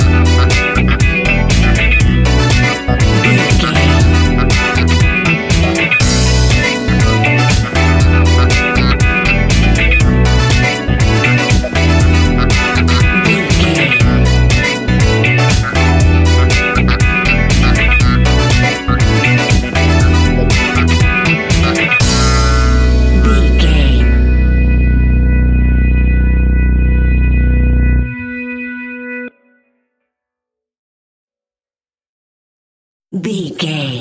Aeolian/Minor
groovy
futuristic
hypnotic
uplifting
drum machine
synthesiser
funky house
disco house
electronic funk
energetic
upbeat
synth leads
Synth Pads
synth bass